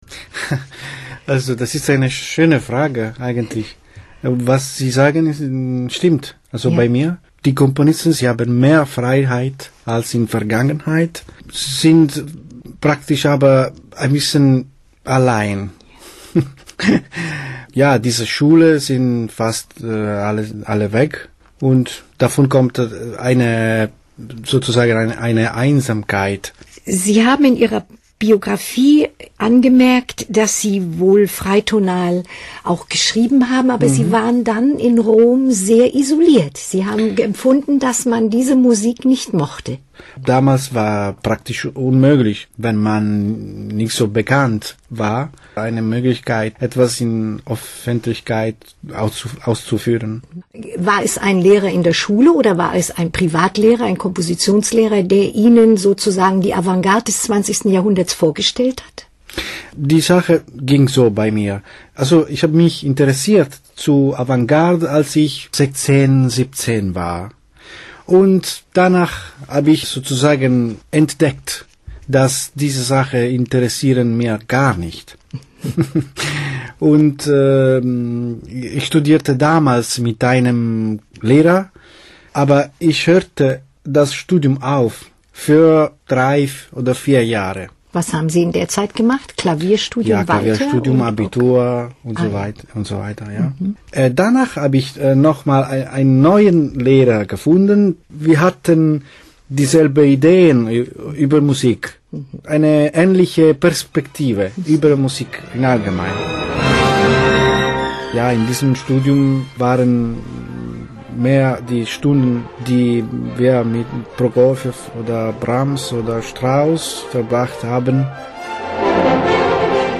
Ritratto radiofonico con intervista ed esempi musicali (MDR Figaro, Halle)